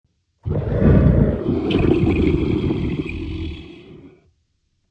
Tiger Creature Roar Fantasy Monster Sfx Bouton sonore